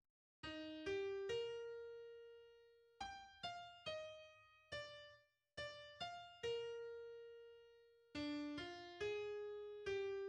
en mi bémol majeur
Effectif Orchestre symphonique
Entrée des premiers violons dans l'Allegro: